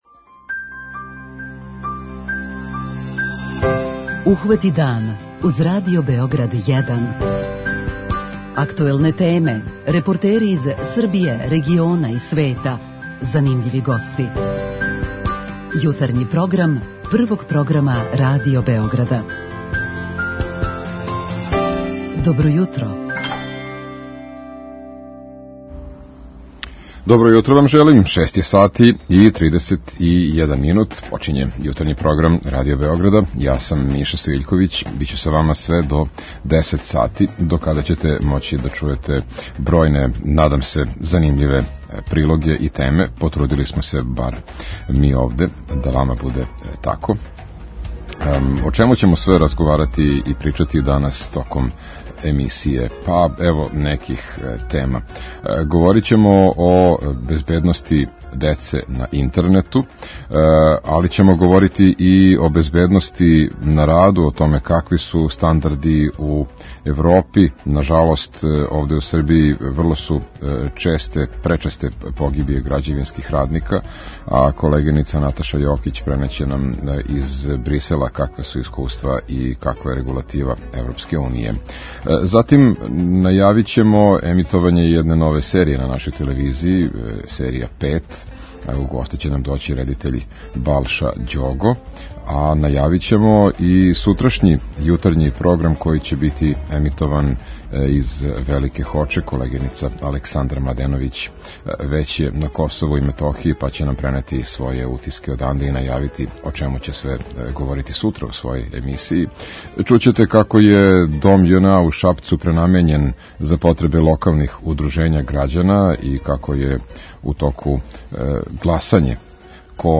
У сусрет 8. марту, питаћемо слушаоце какав је данас положај жена у нашој земљи и шта може да се унапреди.
преузми : 37.65 MB Ухвати дан Autor: Група аутора Јутарњи програм Радио Београда 1!